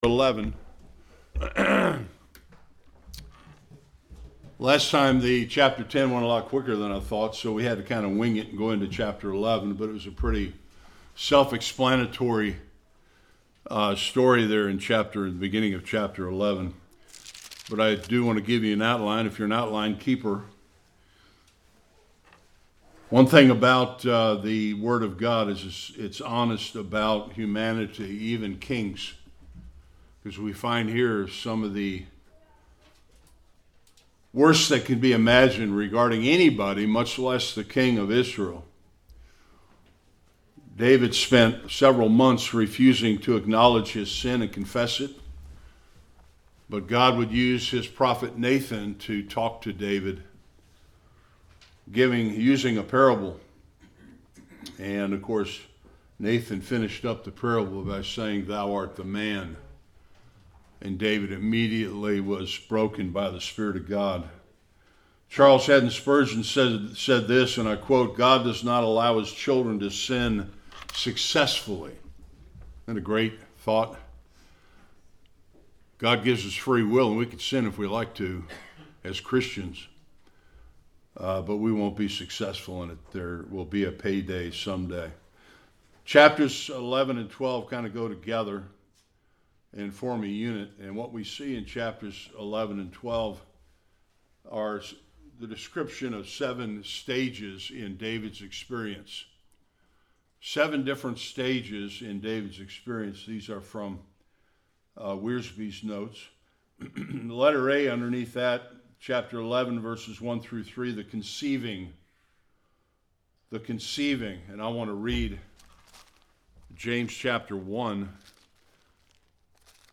Sunday School The tragedy of David and Bathsheba.